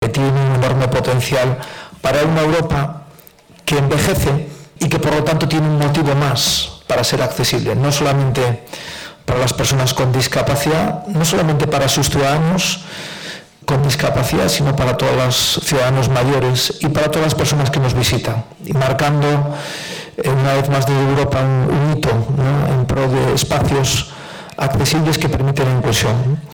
Fundación ONCE acogió, el pasado 7 de noviembre, un encuentro organizado por ‘AccessibleEU’ para impulsar el diálogo entre industria, administraciones públicas y sociedad civil con el objetivo de mejorar la accesibilidad.